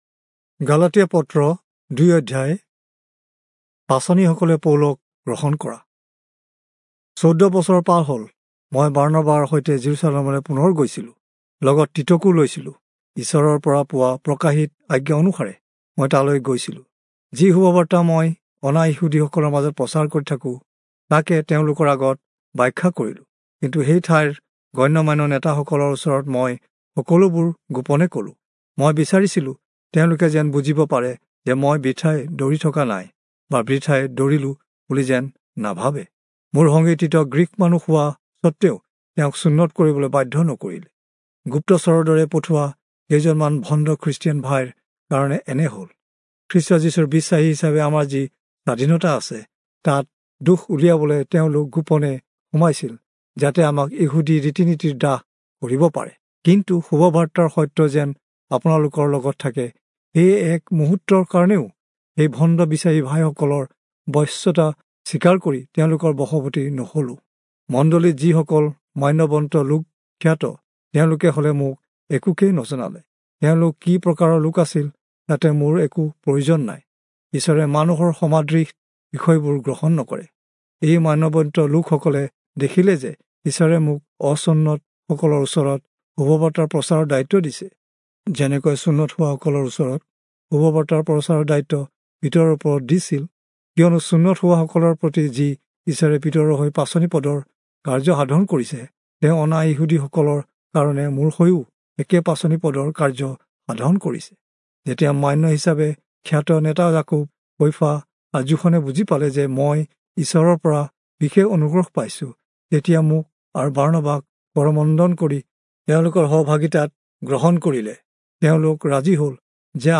Assamese Audio Bible - Galatians 2 in Ocvhi bible version